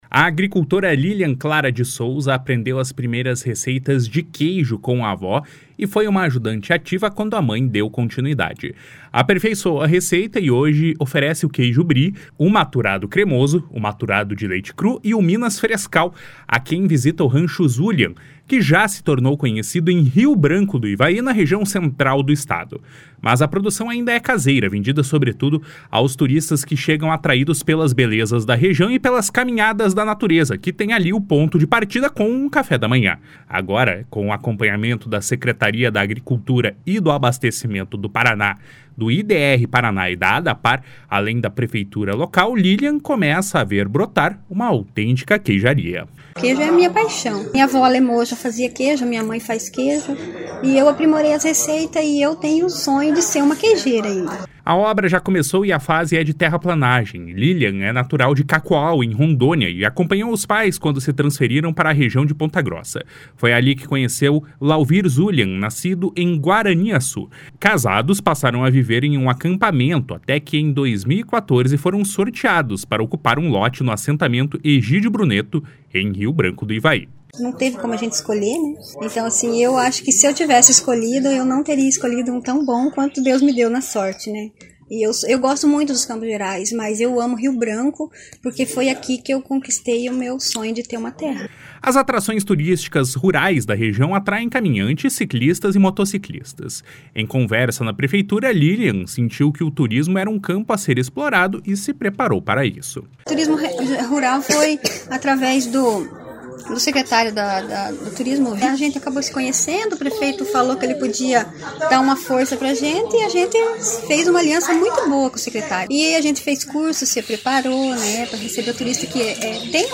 O secretário estadual da Agricultura e do Abastecimento, Norberto Ortigara, em visita à propriedade, disse que o esforço da família é um exemplo para outros produtores. // SONORA NORBERTO ORTIGARA //